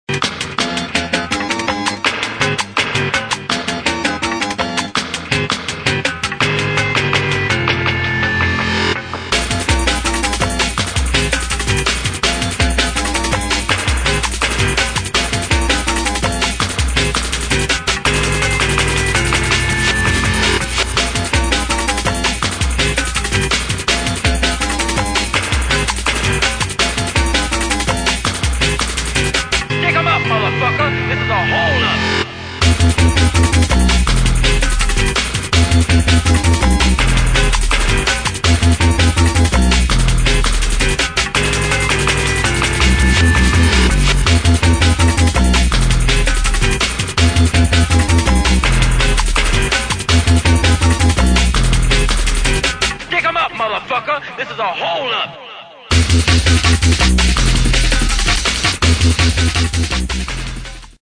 [ DRUM'N'BASS / JUNGLE / OLDSKOOL ]